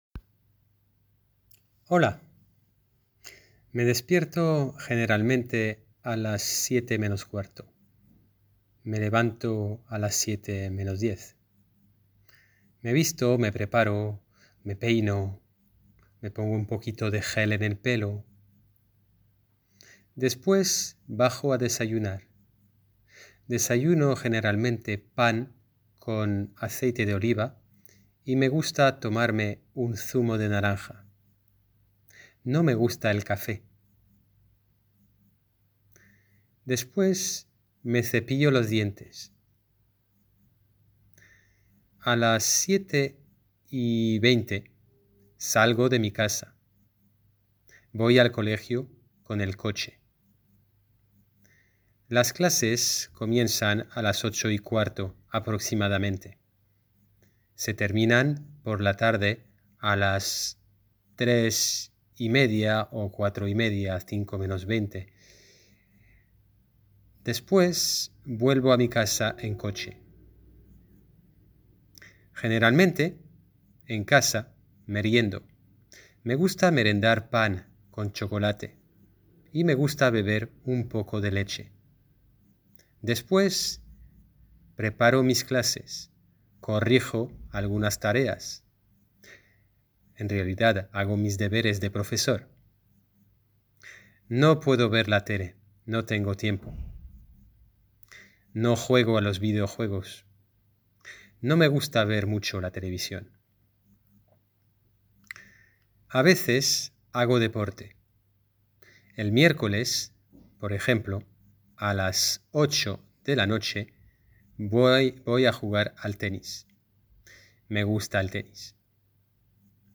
Escucha el ejemplo del profeso